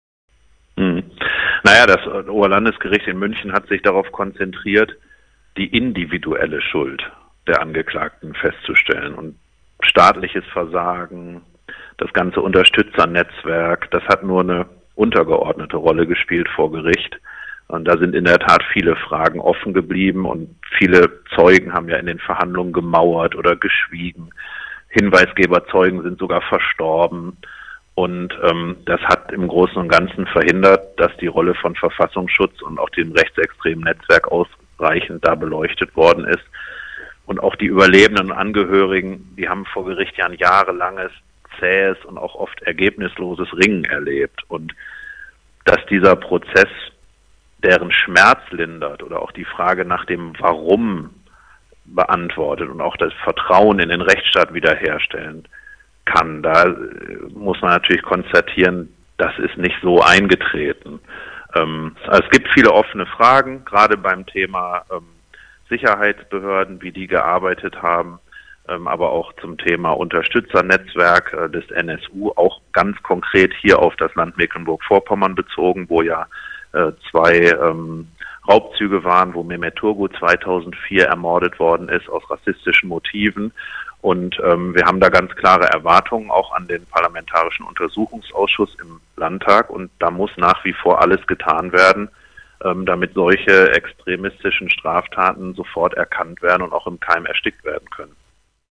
Julian Barlen, Generalsekretär SPD MV & Mitinitiator Endstation Rechts